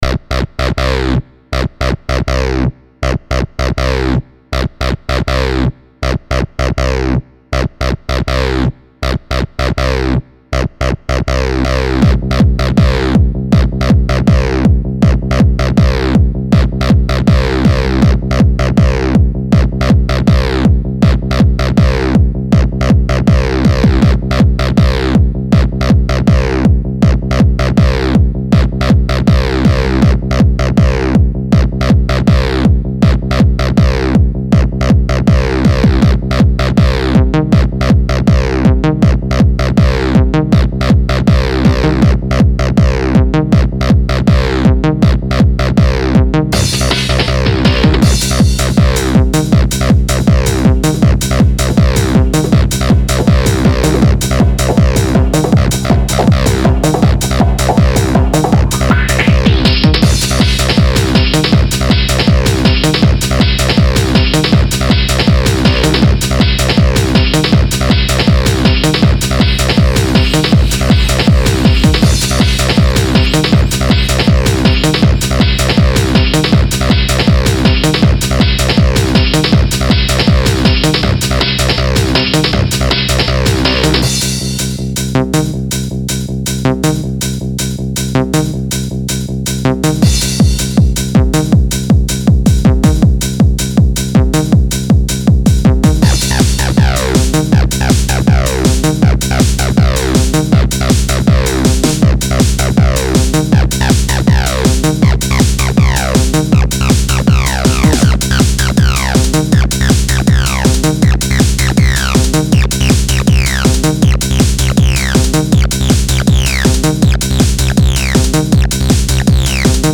acid techno trance edm breakcore,